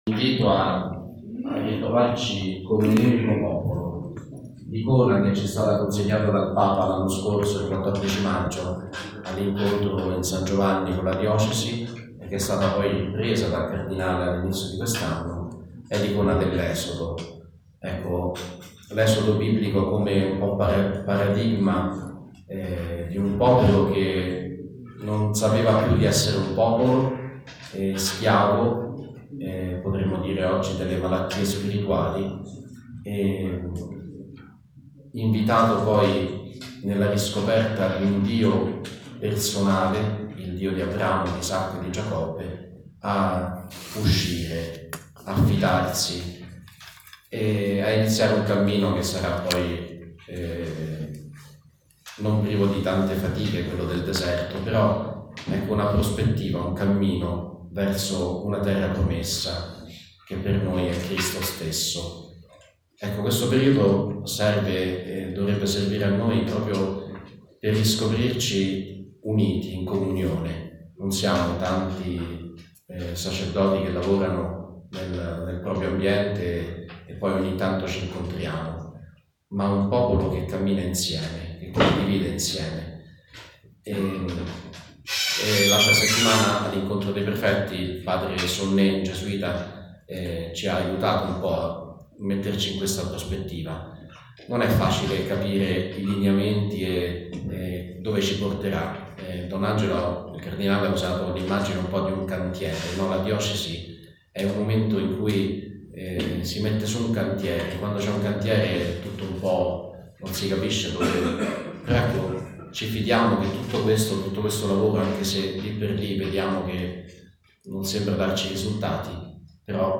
Relazione audio